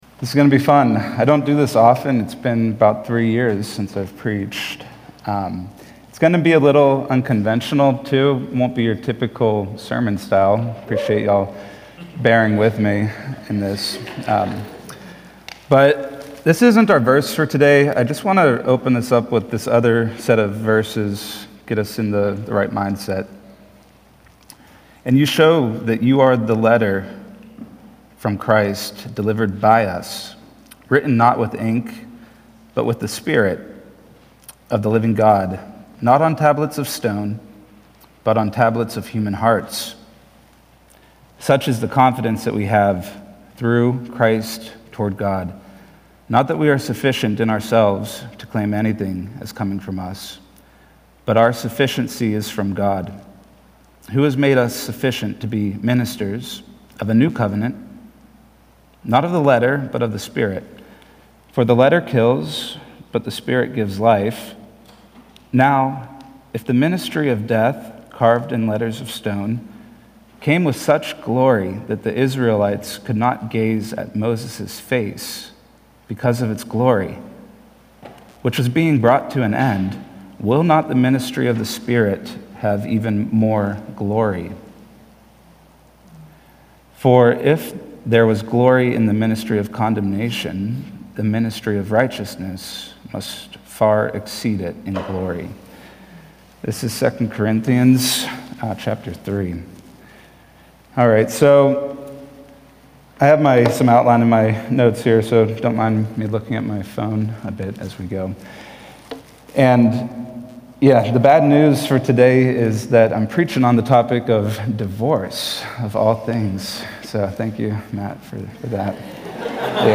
March-8-2026-Full-Sermon.mp3